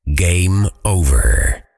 game_over.ogg